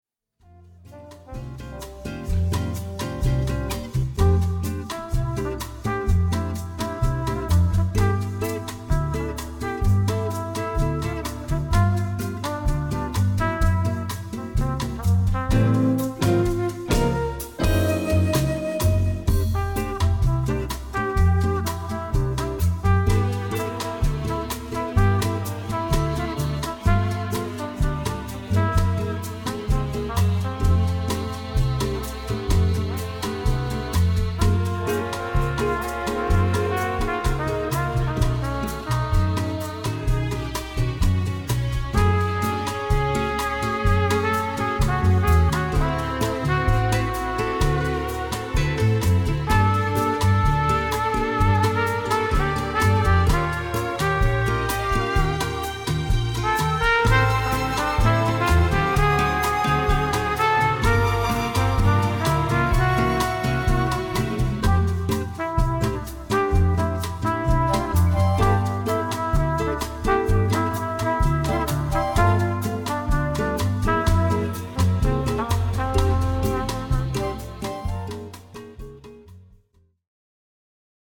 Instrumental - Live gespielt